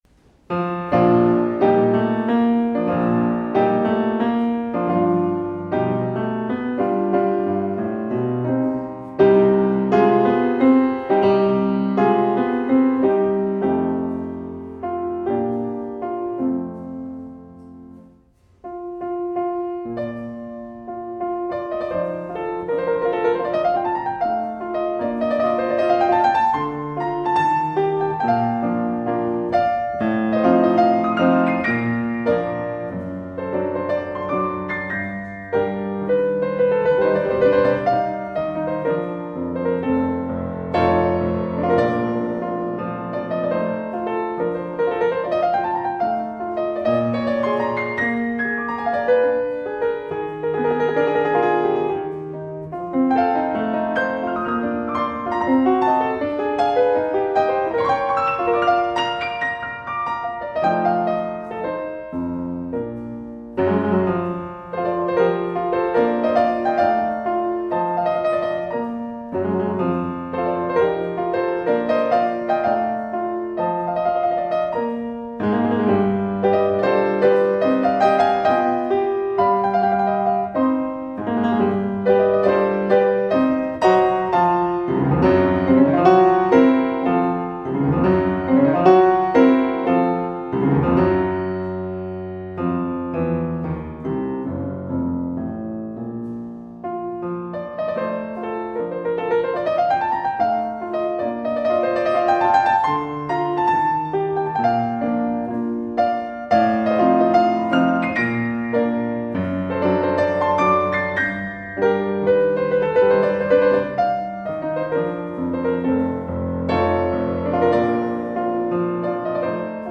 Podczas wykładu spróbujemy poszukać znaków szczególnych Chopinowskiej myśli zarówno w kształtowaniu przez niego linearnego przebiegu utworu (melodia), jak i w oryginalnych, jemu tylko właściwych współbrzmieniach (harmonia).